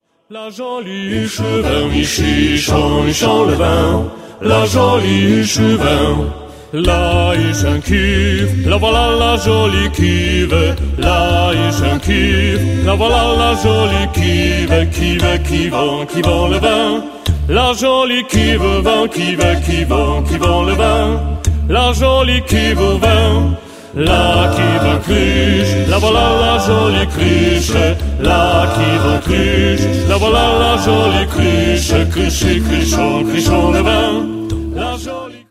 (sł. i mel. trad.)